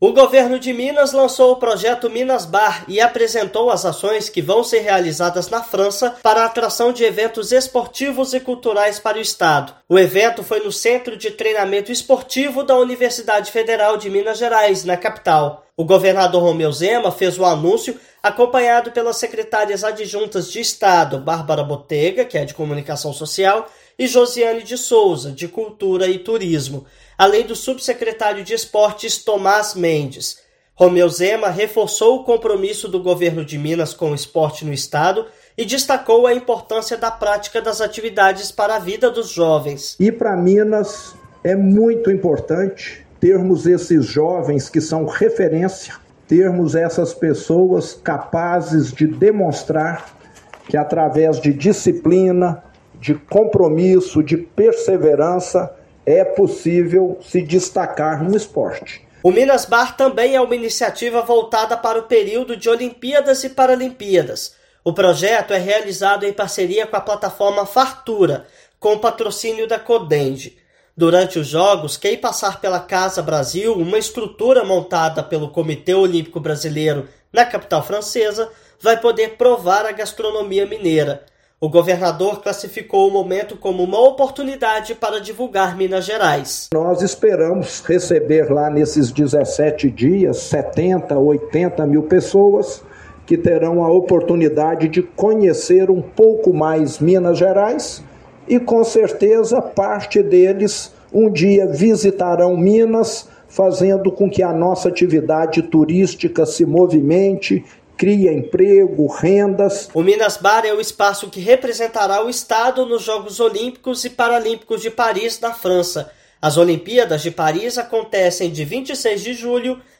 Atletas, público e turistas terão a oportunidade de conhecer e vivenciar a cultura e a culinária mineiras durante as competições, em área temática no Parc la Villete, na capital francesa. Ouça matéria de rádio.